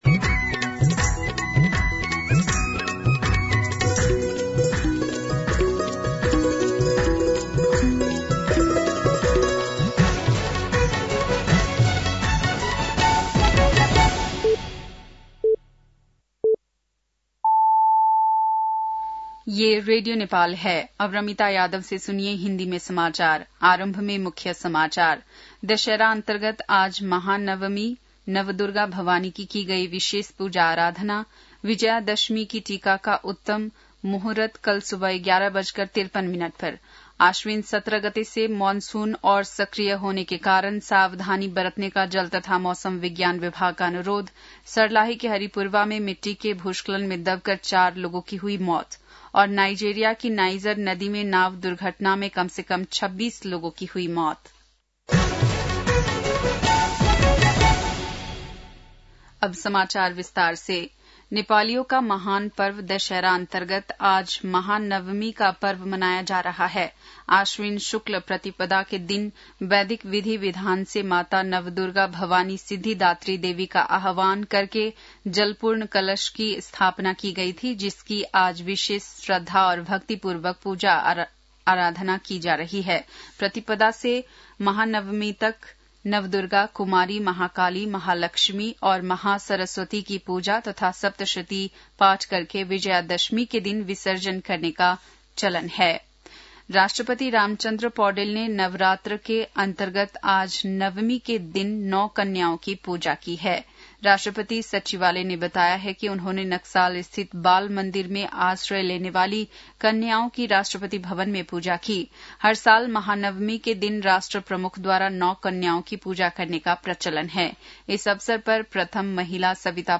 बेलुकी १० बजेको हिन्दी समाचार : १५ असोज , २०८२
10-pm-hindi-news-6-15.mp3